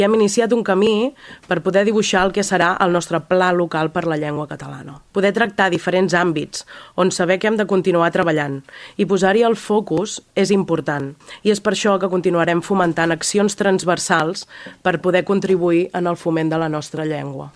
Ho explicava dijous passat durant al sessió del ple municipal, en que va expressar el compromís de treballar perquè el català sigui visible i accessible per tota la ciutadania i en tots els àmbits de la vida.